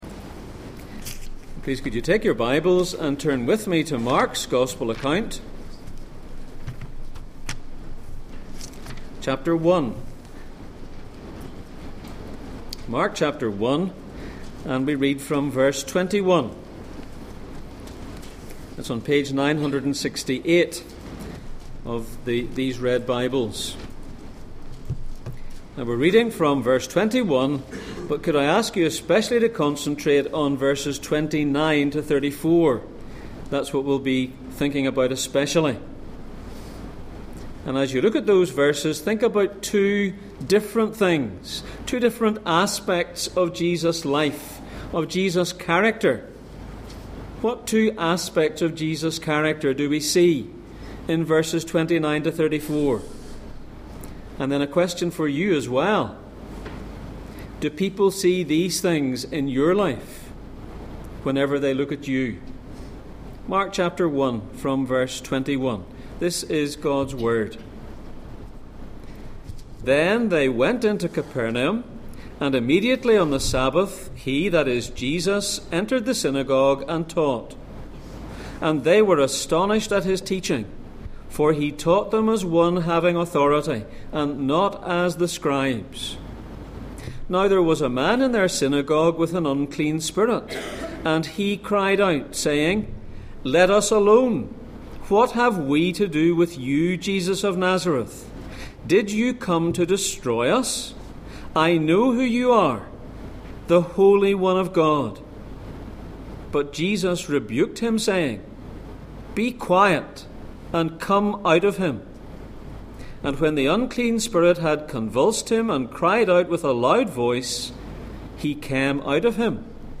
Passage: Mark 1:29-34, Ephesians 2:1-3 Service Type: Sunday Morning